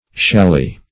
shalli - definition of shalli - synonyms, pronunciation, spelling from Free Dictionary
shalli - definition of shalli - synonyms, pronunciation, spelling from Free Dictionary Search Result for " shalli" : The Collaborative International Dictionary of English v.0.48: Shalli \Shal"li\, n. See Challis .